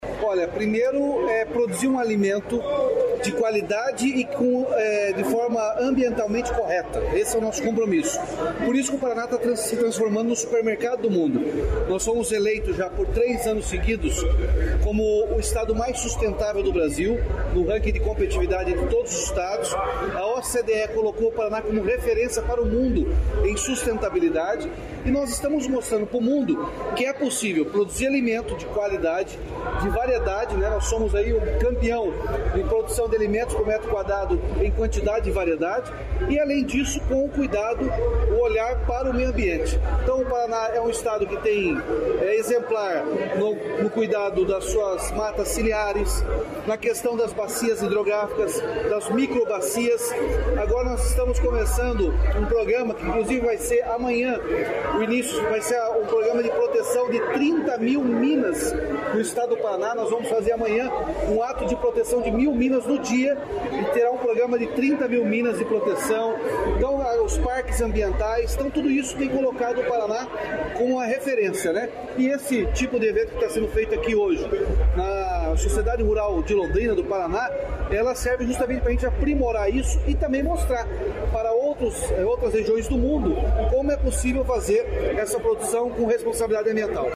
Sonora do governador Ratinho Junior sobre exemplo de inovação e sustentabilidade no Paraná